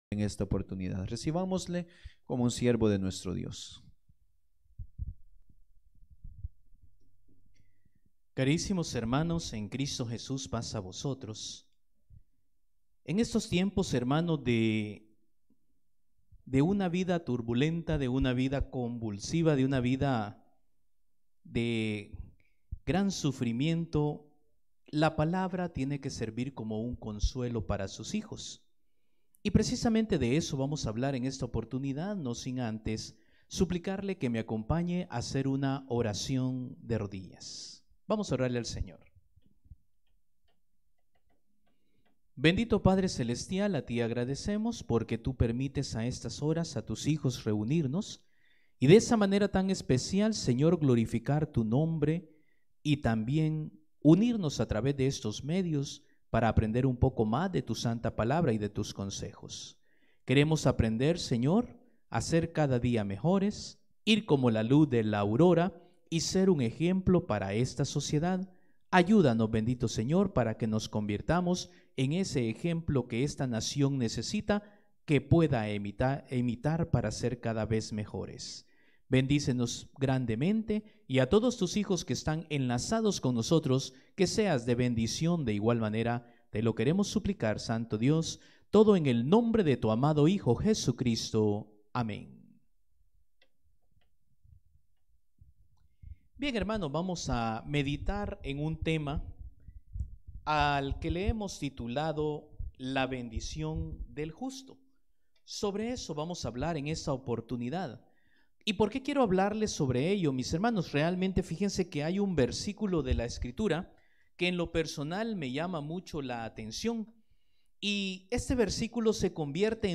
Predicaciones - Iglesia de Dios